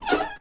carSuspension1.ogg